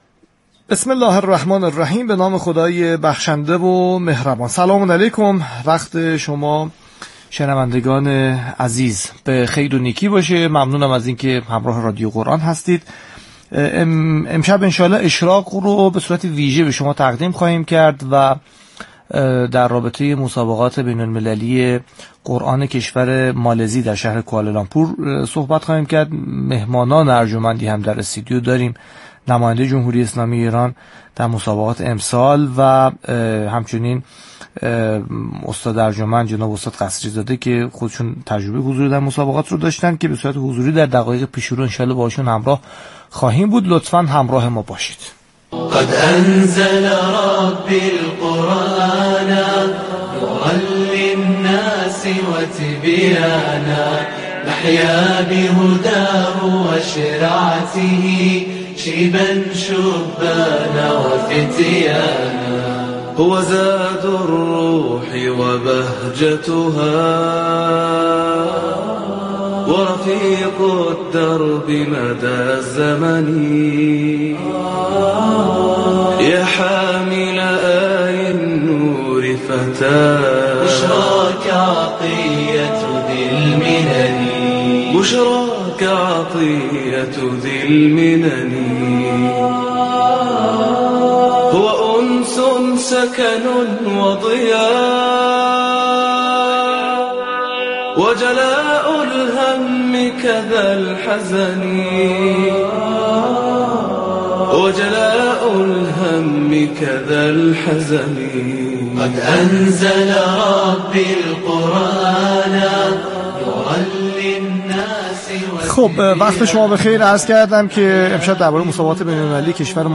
ویژه‌برنامه زنده «اشراق» که اول آبان از شبکه رادیویی قرآن پخش شد، به تحلیل و بررسی مسابقات بین‌المللی قرآن کریم کشور مالزی اختصاص داشت.